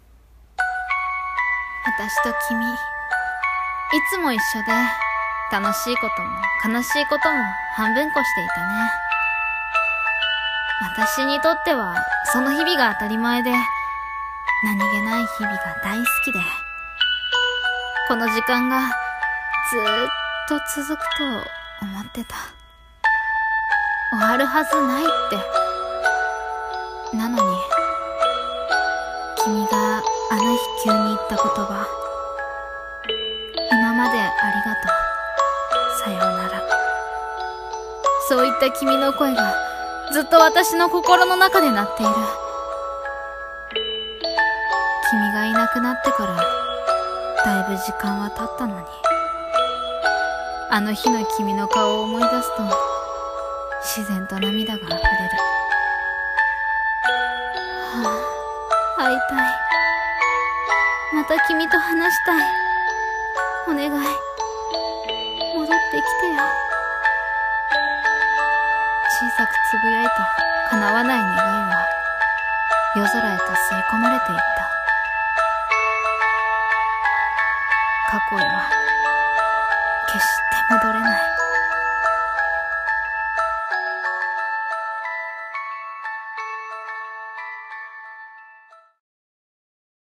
【声劇台本】あの日へまた…